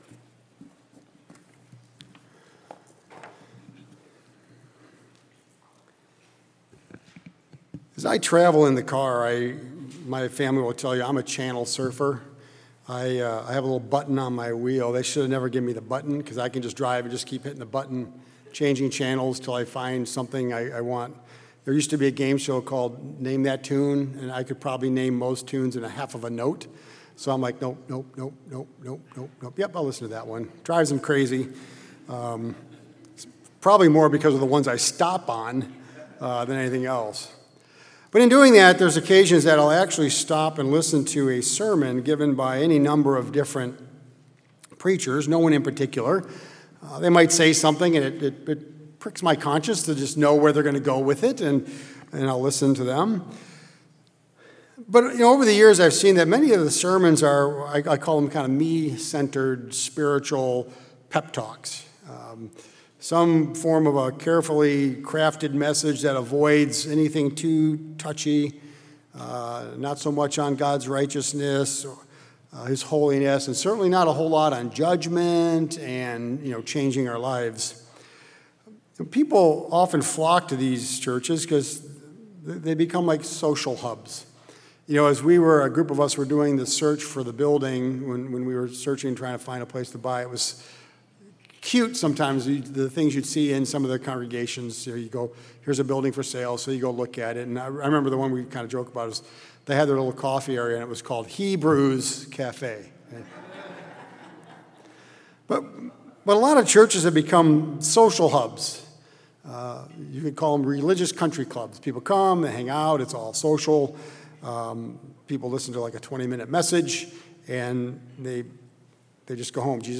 Repentance, a central theme of Christ ministry, seems to be a foreign concept to them and something they do not want to hear. In this message, we are going to discuss a few attributes or roadblocks that may be pulling us away from God, but we do not know it.